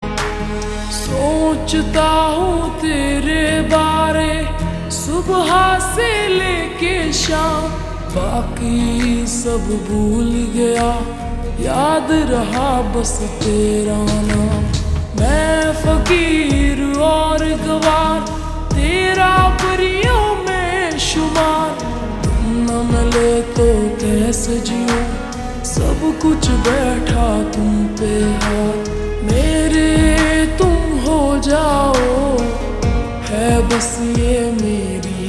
(Slowed + Reverb)